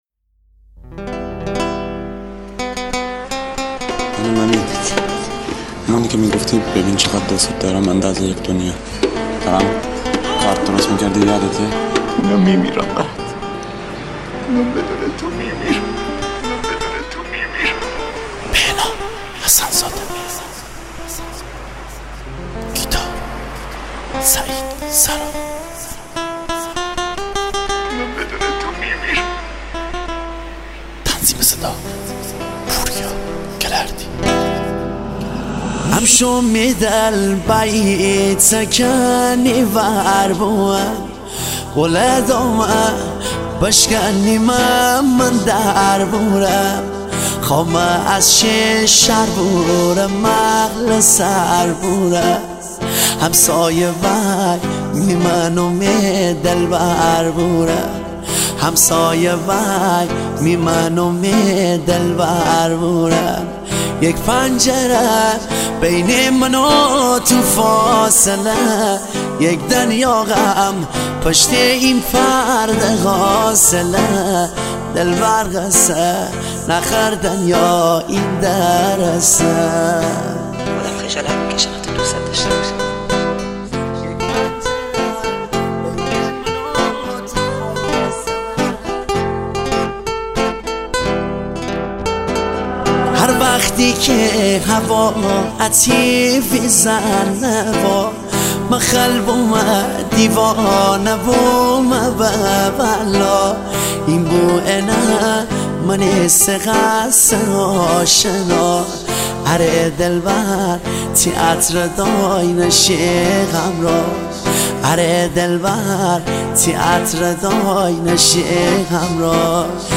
با ترکیب منحصربه‌فرد ملودی‌های سنتی و ترکیب‌های مدرن